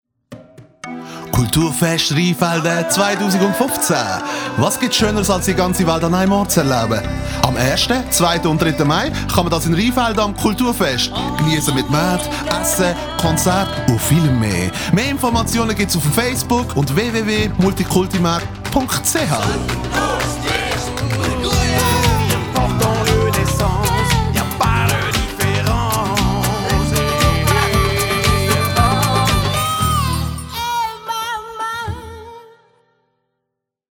Multikulti-Werbespot
Multikulti-Werbespot.mp3.mp3